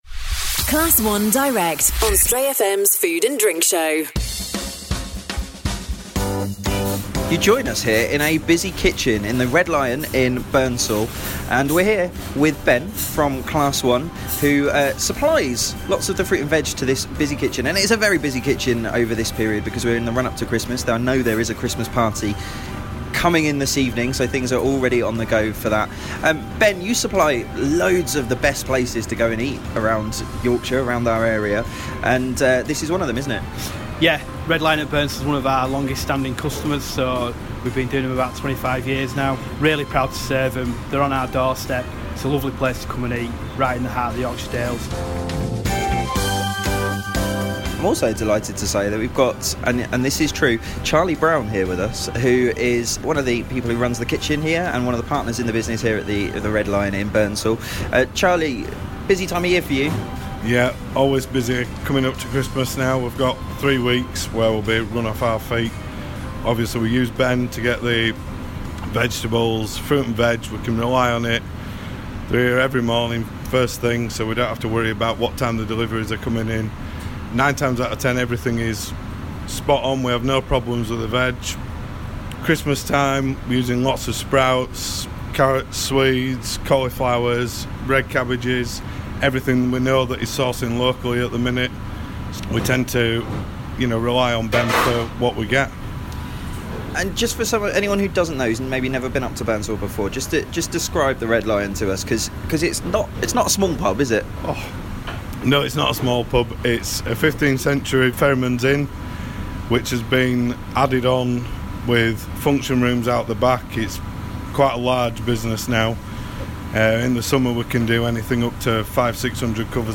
Join us for a trip to the Red Lion in Burnsall who get their vegetables from Class One Direct. How are they planning to make sure that Christmas goes without a hitch?